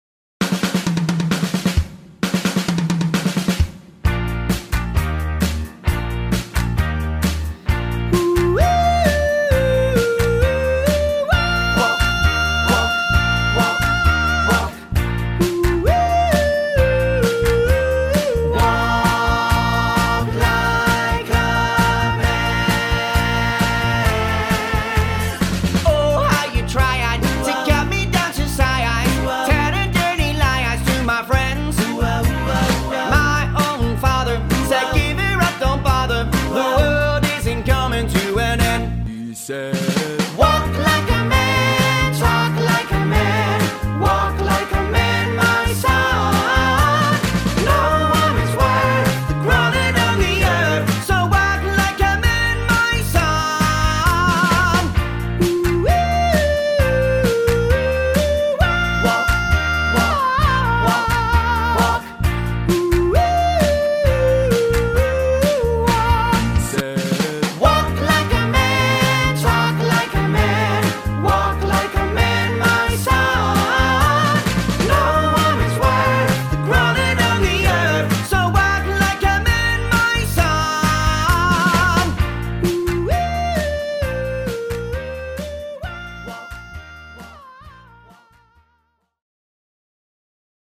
Outstanding Vocals & Harmonies